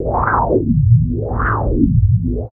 69 MD WIND-R.wav